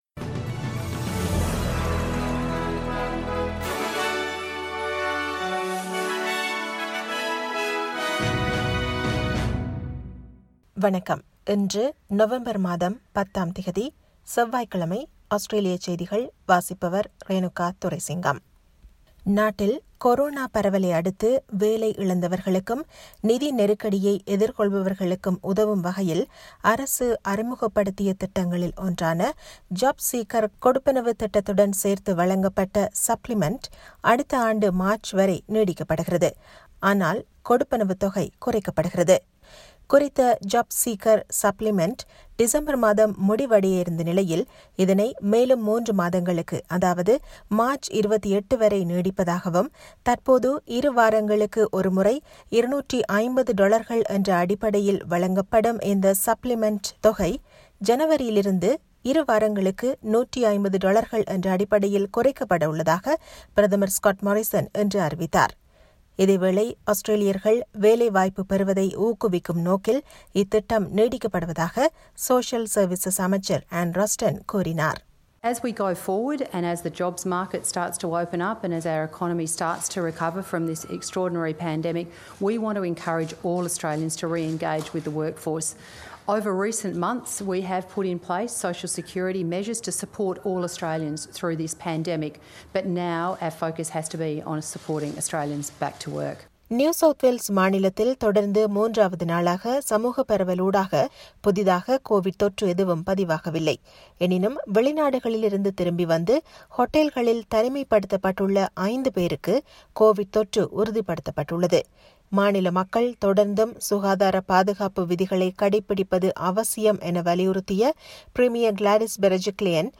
Australian news bulletin for Tuesday 10 November 2020.